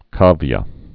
(kävyə)